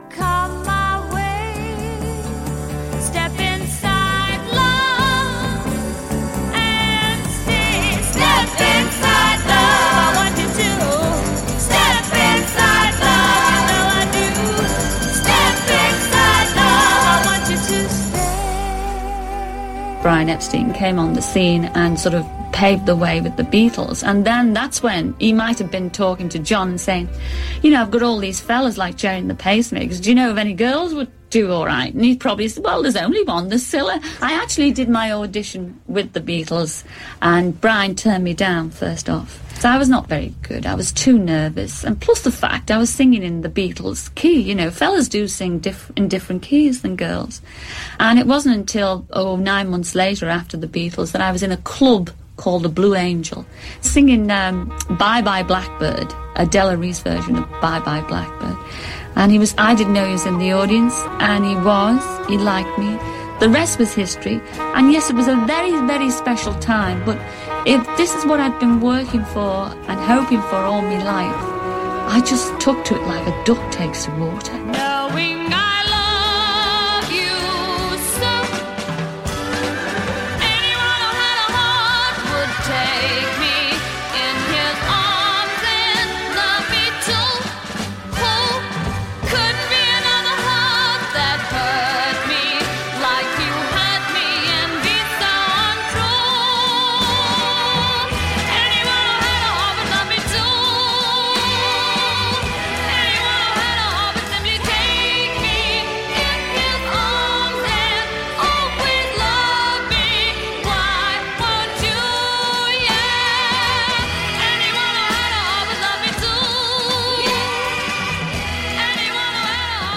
Whether it's the Swinging 60s or Blind Date, Cilla Black was part of the British showbusiness scene for half a decade. Here she talks about the break she got with Beatles manager Brian Epstein.